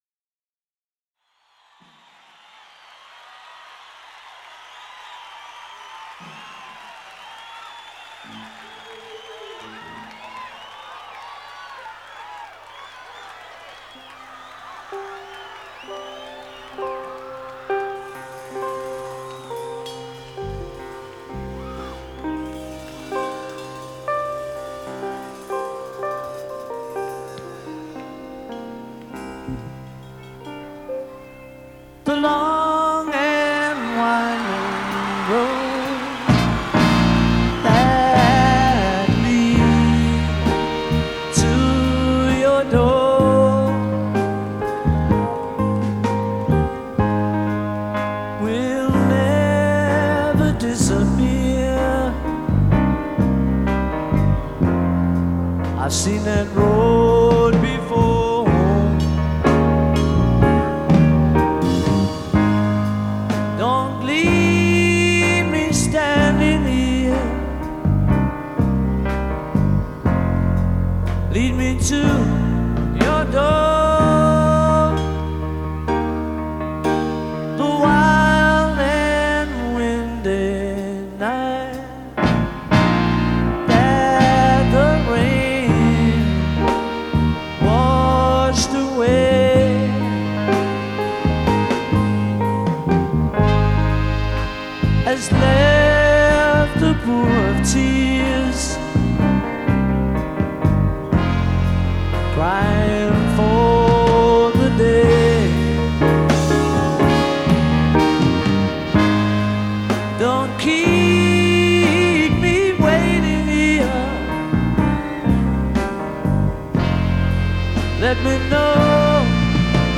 баллада